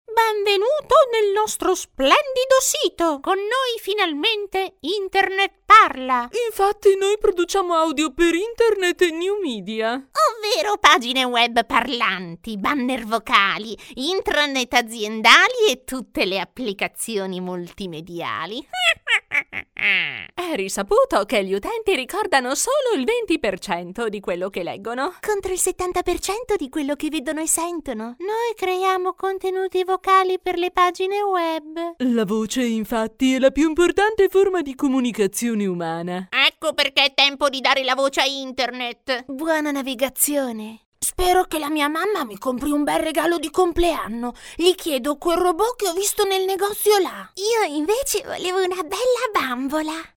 Mother-tongue Italian professional Voiceover Talent.
Sprechprobe: eLearning (Muttersprache):